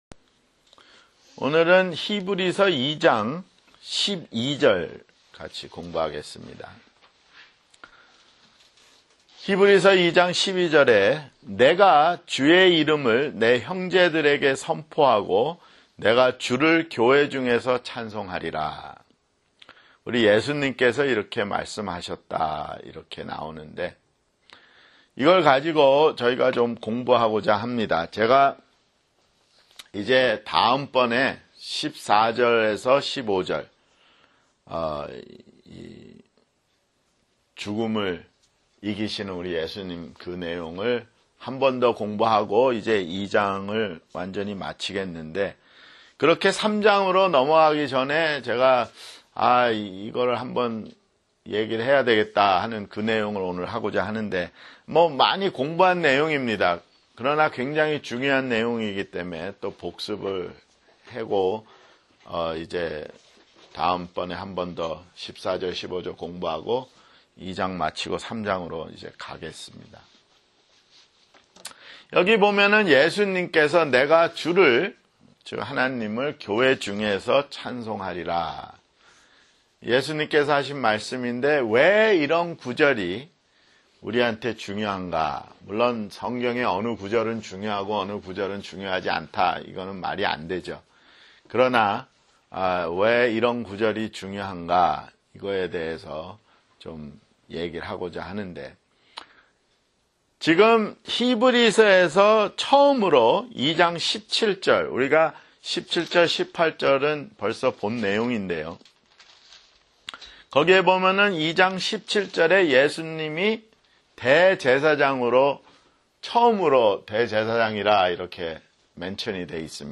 [성경공부] 히브리서 (14)